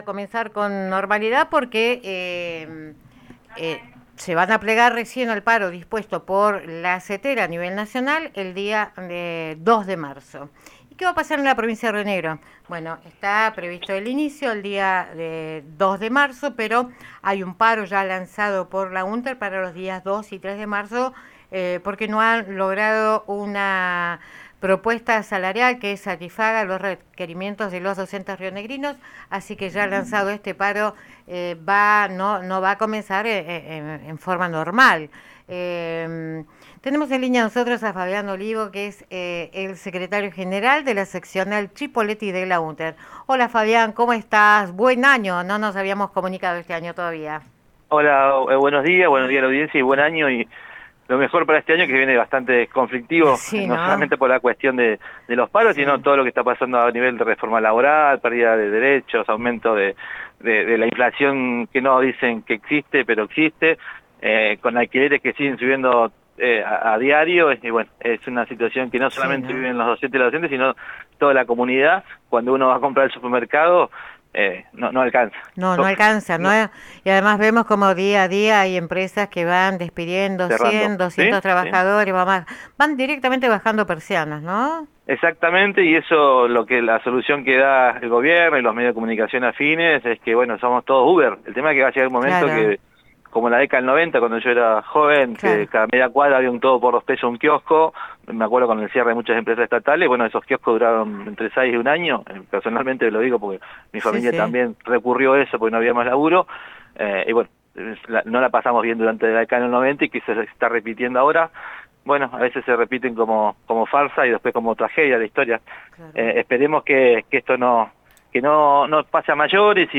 Entrevista a Lorena Matzen, legisladora UCR. 03 de marzo 2026